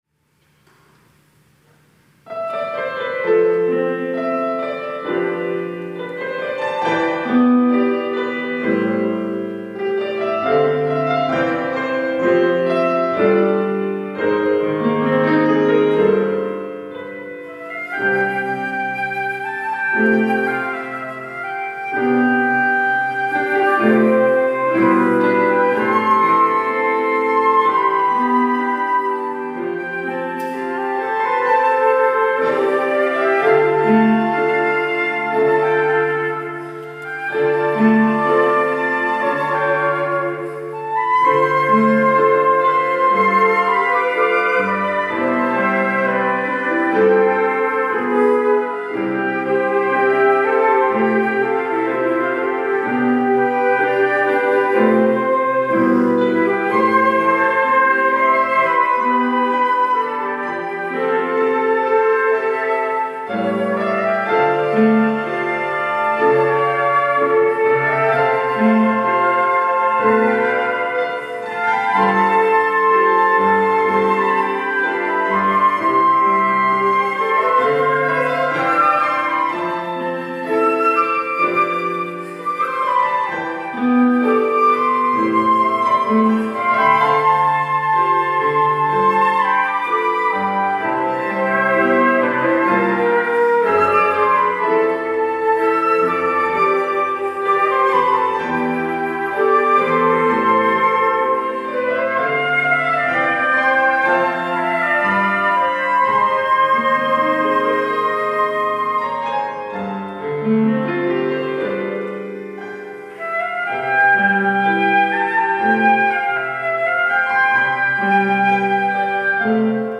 특송과 특주 - 전능하신 나의 주 하나님
하이피플 앙상블